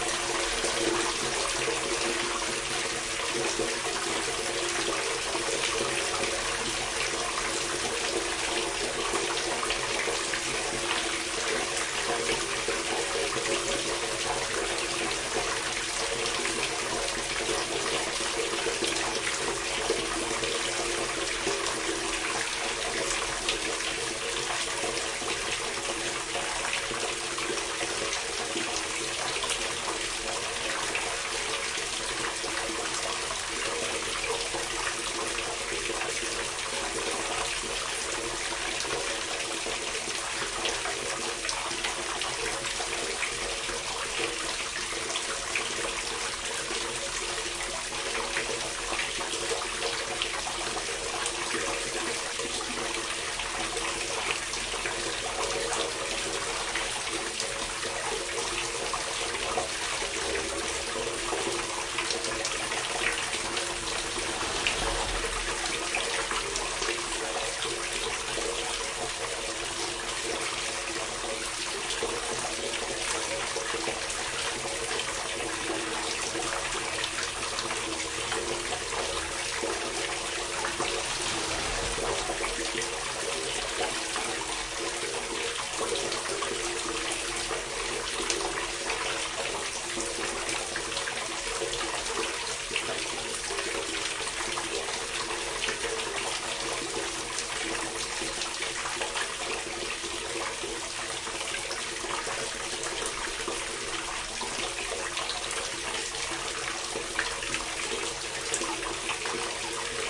随机 " 下水道排水管关闭3
Tag: 漏极 下水道 关闭